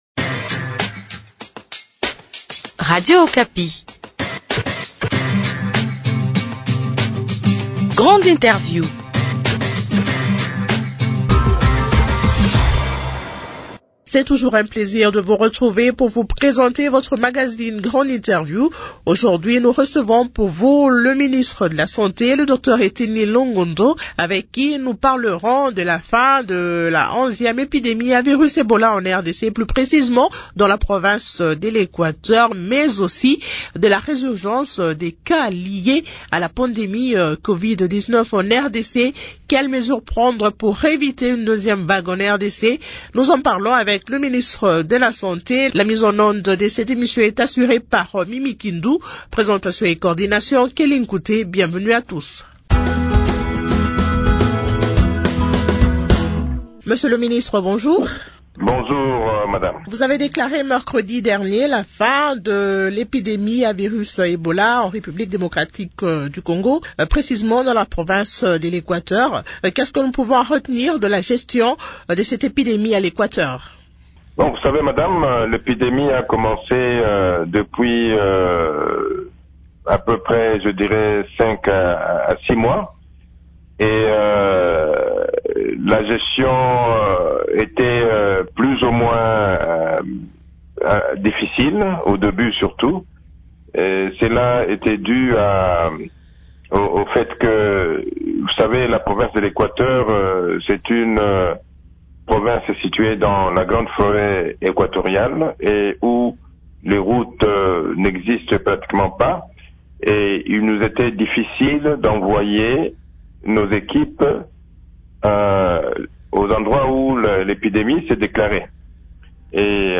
Eteni Longondo, ministre de la santé est l’invité du magazine Grande Interview.